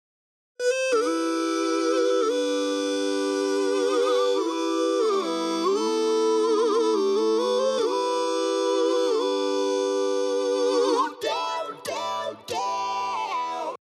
Talkbox Effect